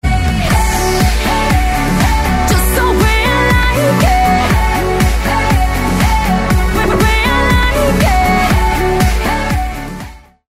GenrePop